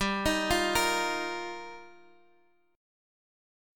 Gm7#5 Chord